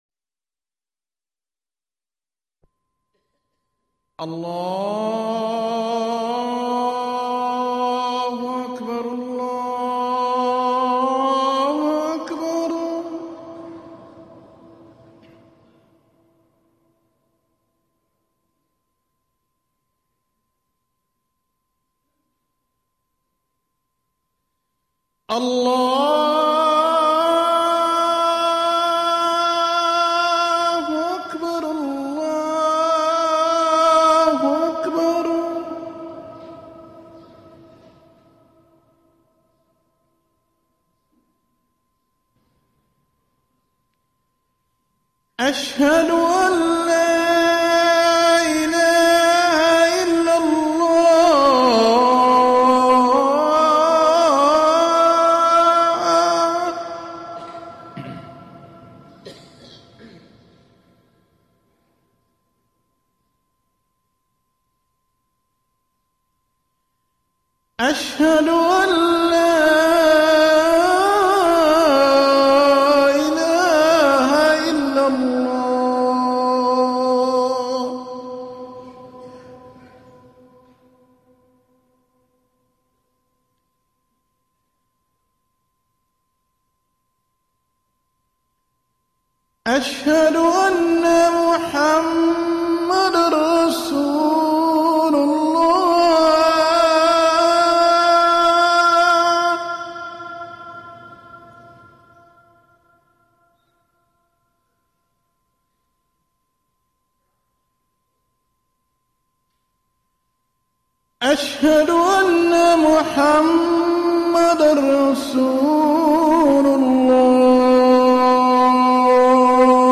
أذان المغرب
المكان: المسجد النبوي